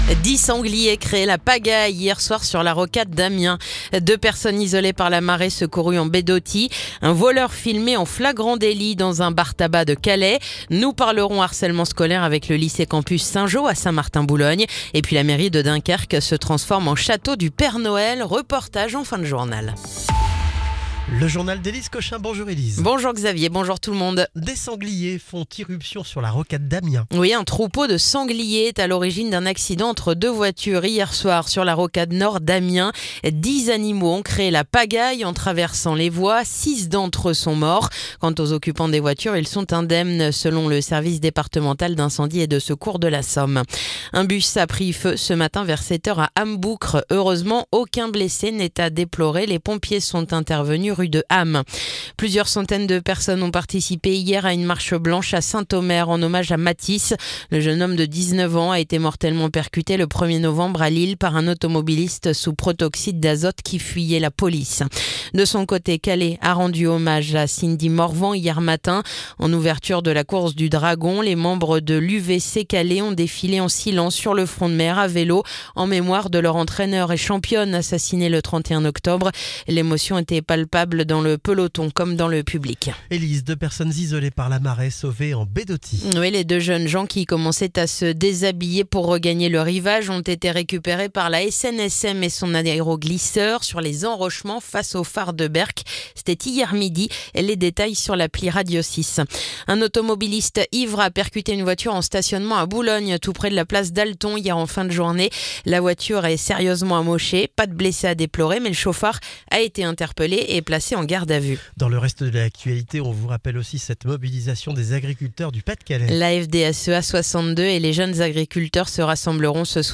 Le journal du mercredi 12 novembre